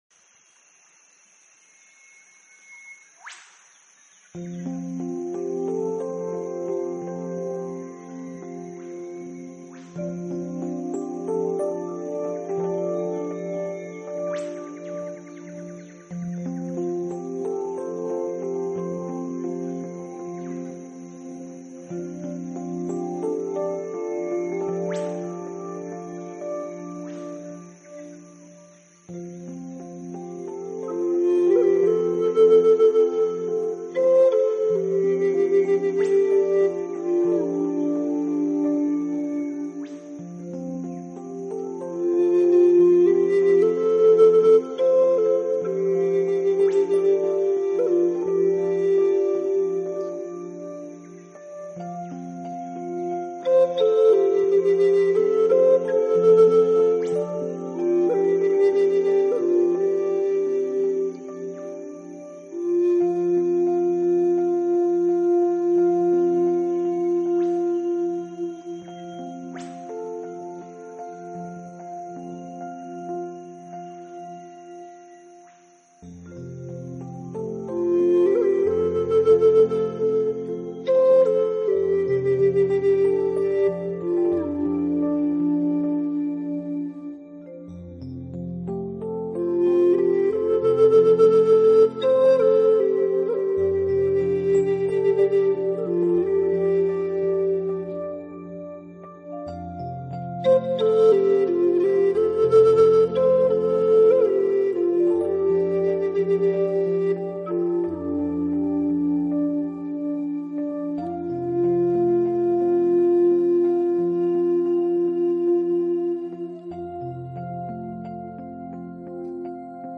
新世纪纯音乐
专辑语言：纯音乐
这种来自美国土着之笛的神秘声音漂浮在脑
Zen Garden（一种带来和平和宁静的声音的表现手法），非常值得一听！